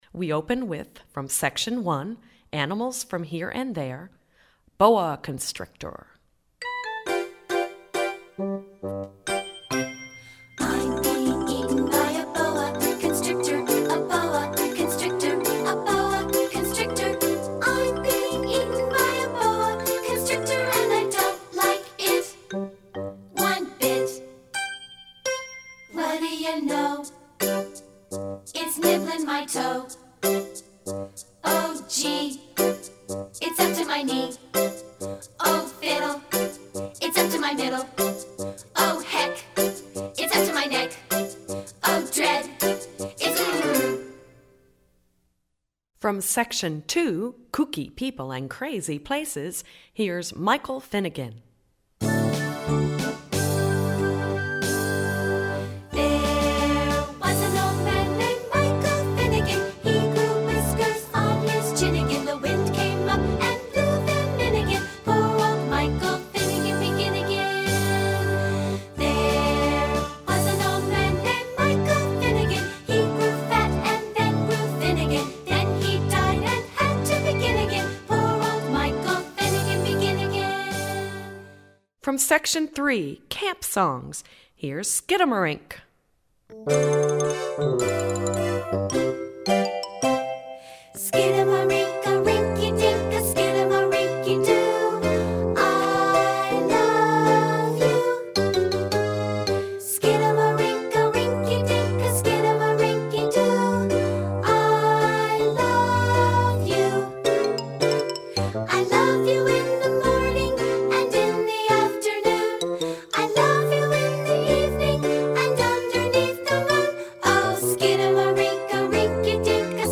Voicing: PVG Collection